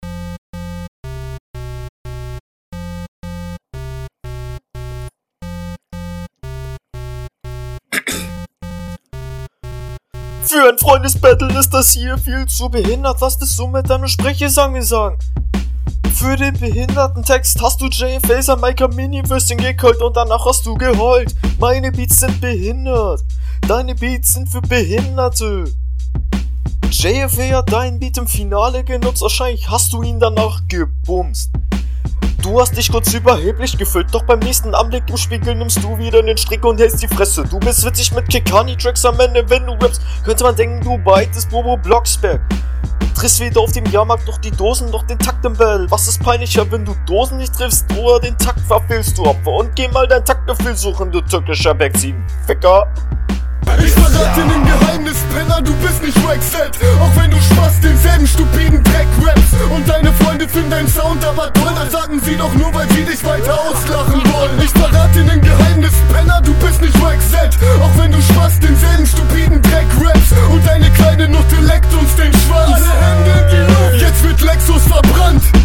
Der Stimmeinsatz ist etwas schlechter, dafür …